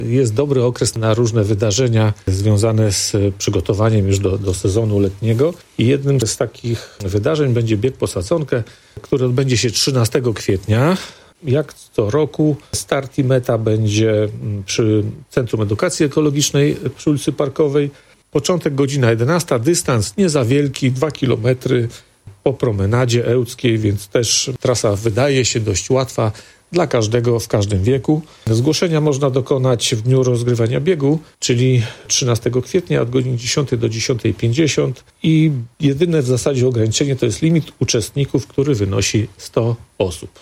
– To już w najbliższą niedzielę – mówi Mirosław Hołubowicz, zastępca prezydenta Ełku.